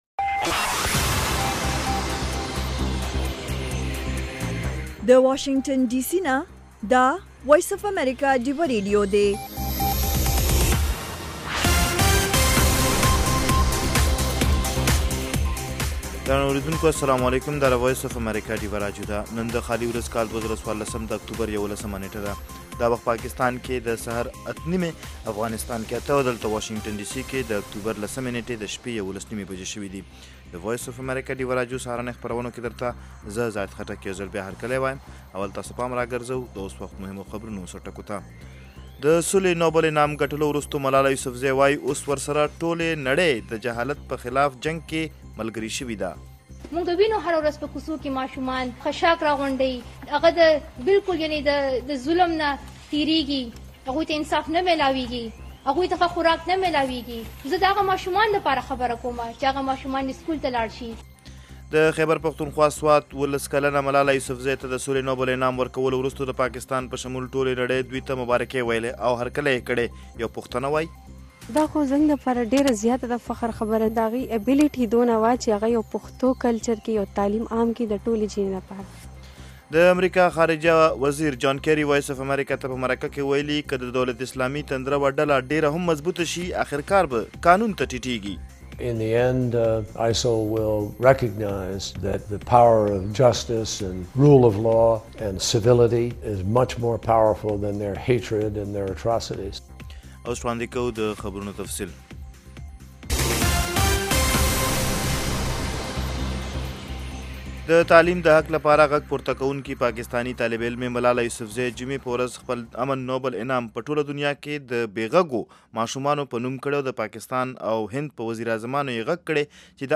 خبرونه - 0330